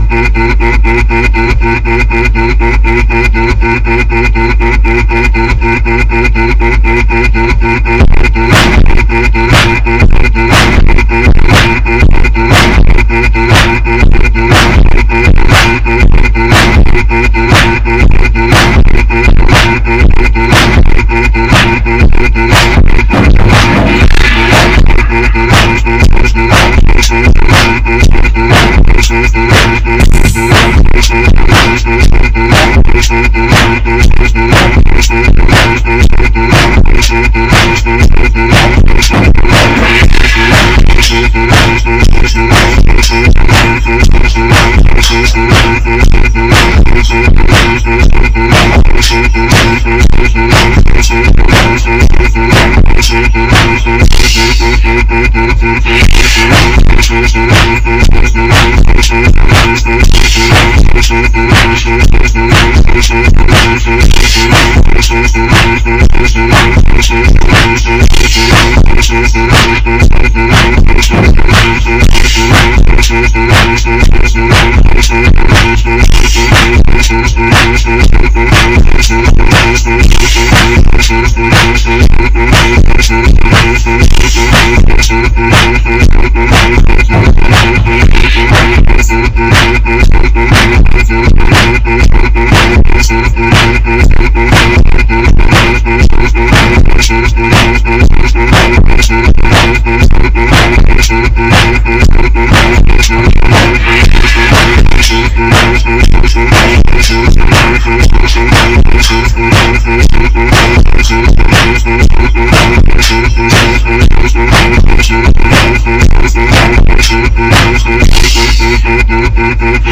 방금 만든거라 (샘플이랑 드럼밖에 없음) 3분동안 아무것도 없으니 추가 요소 뭐 넣을지 추천해주세요